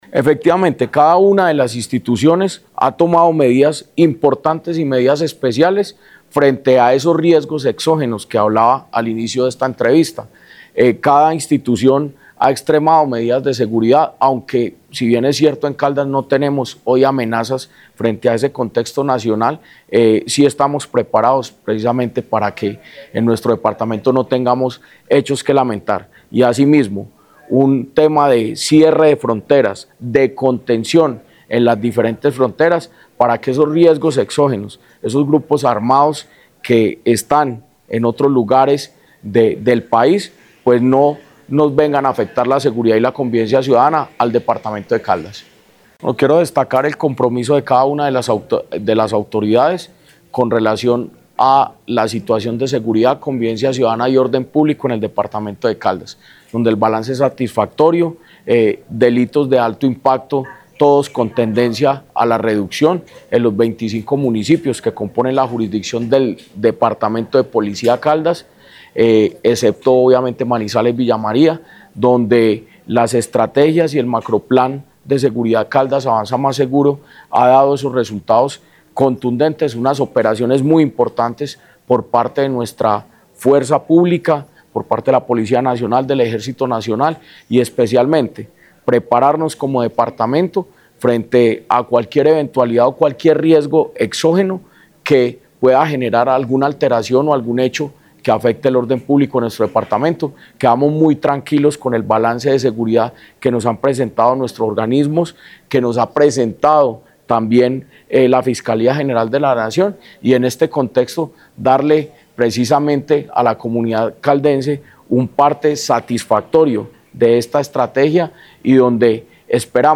Jorge Andrés Gómez Escudero, secretario de Gobierno de Caldas.